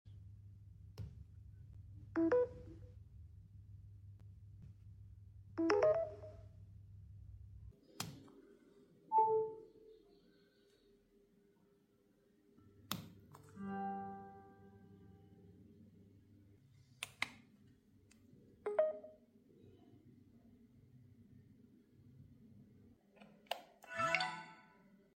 Charging Sound Across Different Devices Sound Effects Free Download
charging sound across different devices sound effects free download